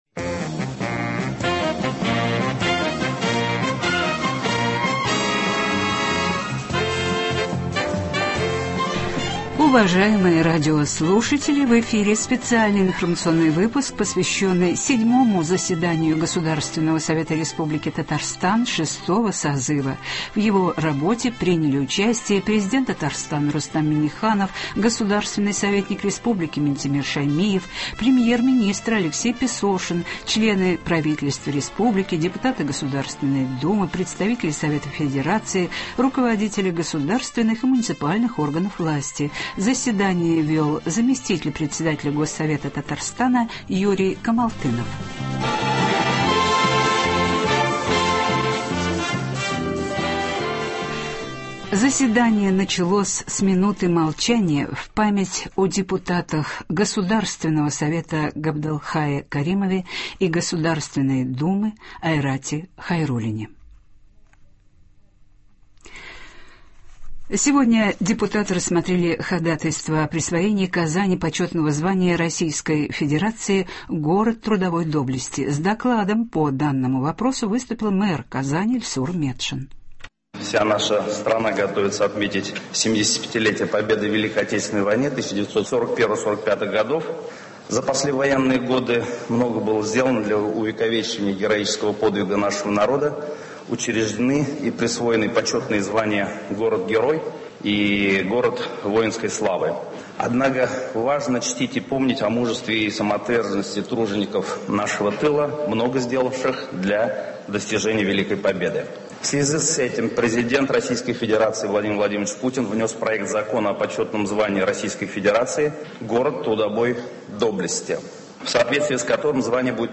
В эфире специальный информационный выпуск , посвященный 7 заседанию Государственного Совета Республики Татарстан 6-го созыва.
Сегодня депутаты рассмотрели ходатайство о присвоении Казани почетного звания Российской Федерации «Город трудовой доблести». С докладом по данному вопросу выступил мэр города Ильсур Метшин.